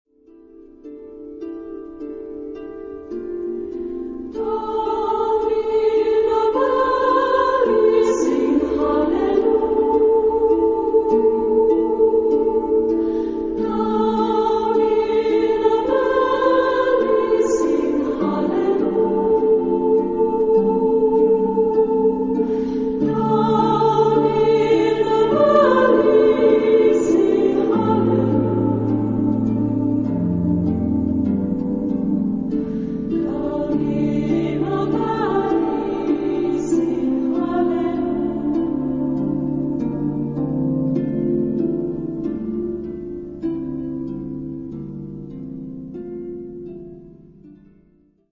Genre-Style-Form: Christmas song ; Sacred
Mood of the piece: contemplative ; gentle
Instrumentation: Keyboard  (1 instrumental part(s))
Tonality: D minor